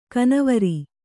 ♪ kanavari